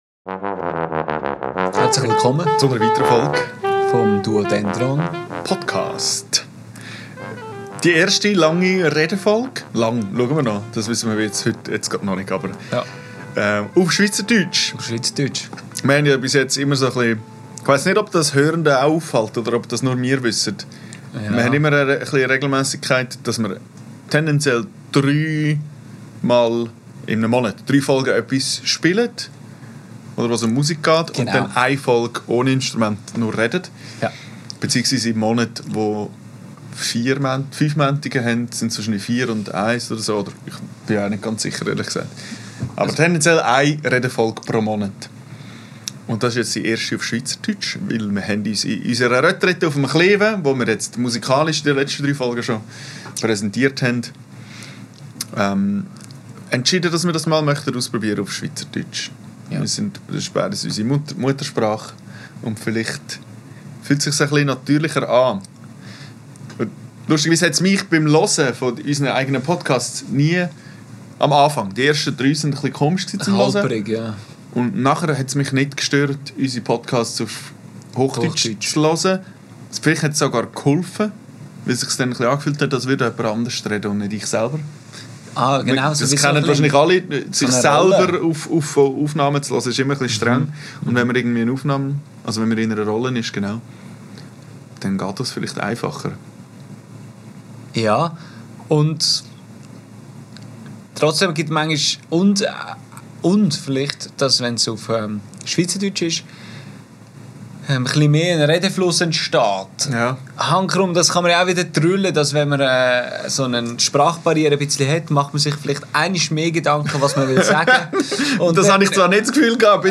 Die erste Laber-Folge auf Schweizerdeutsch! Weil wir machen können, was wir wollen ;) In dieser Folge reden wir über wie berühmt wir gerne wären und was überhaupt realistisch wäre.